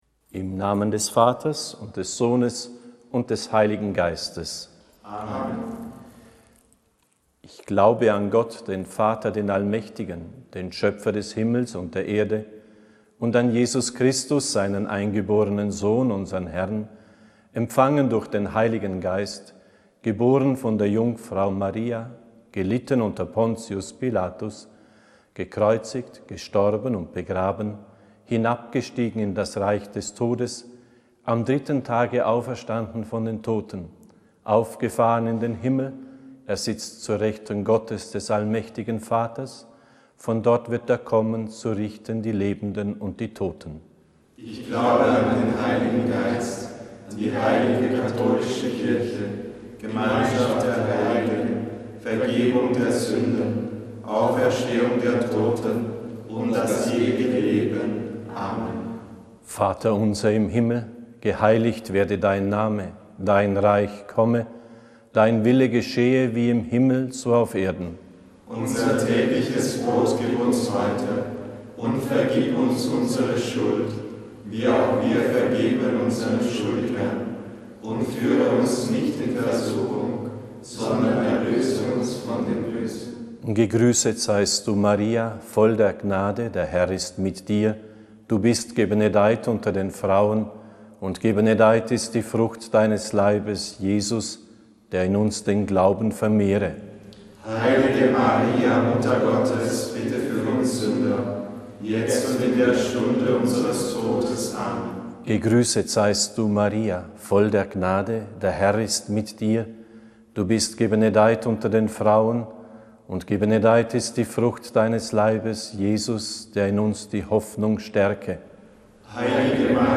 Aufgezeichnet wurde das Rosenkranzgebet in der Katharinenkapelle des Priesterseminars der päpstlichen Hochschule in Heiligenkreuz (Österreich).